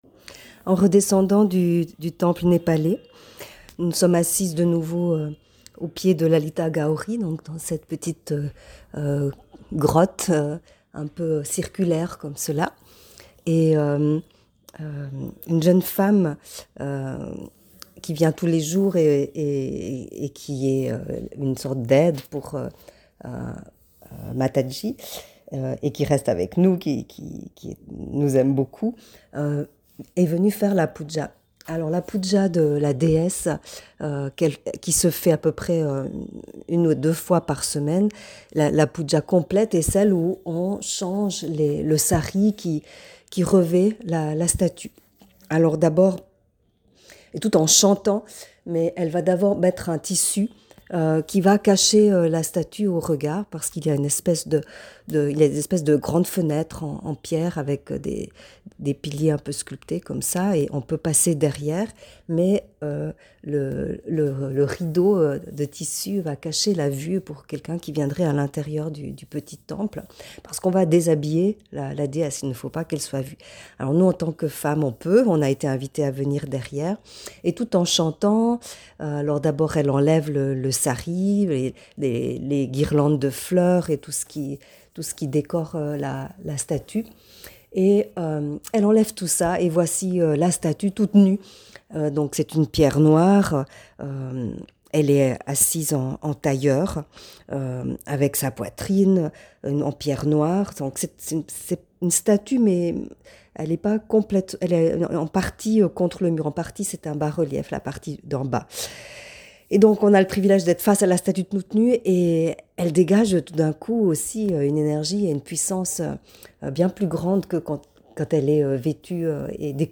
Aujourd’hui, la puja (rite d’offrande et d’adoration) de la déesse Lalita Gauri, dans son temple à Varanasi (Bénarès).
Puja_Lalita_Gauri.mp3